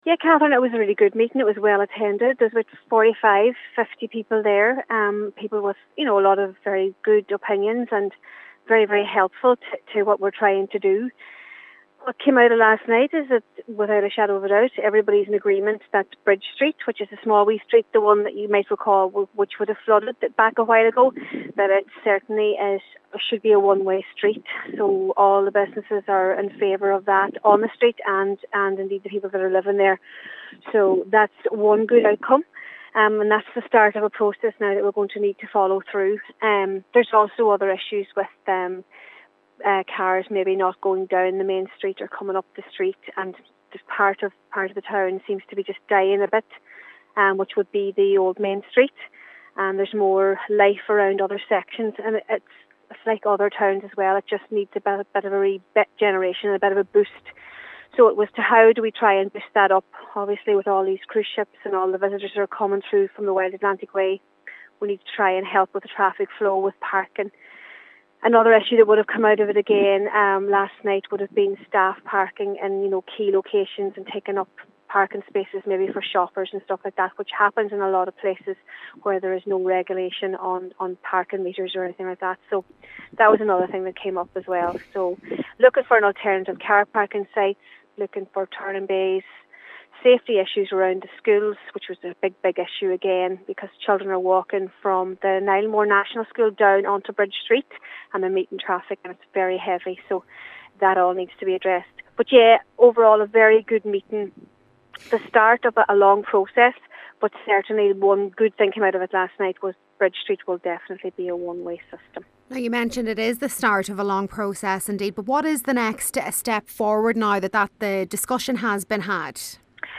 Cllr. Kennedy says it’s the start of a long process but positive that it has begun: